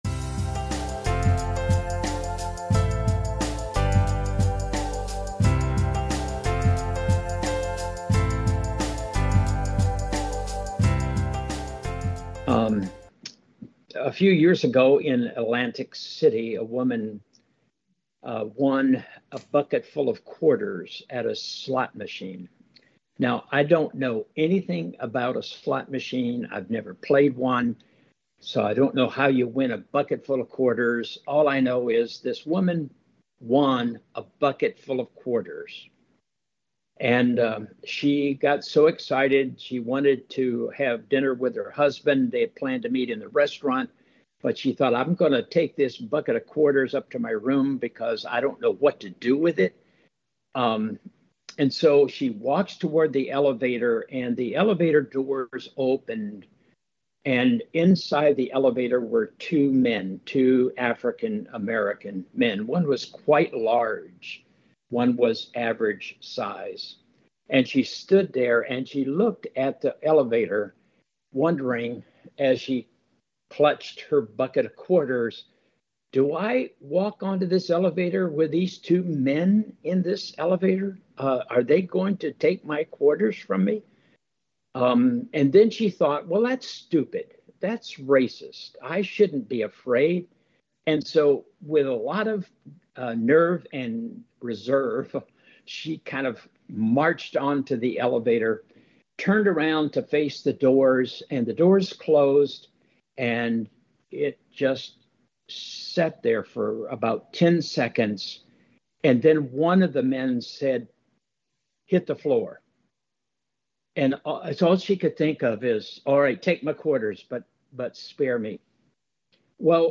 NBC Audio Chapel Services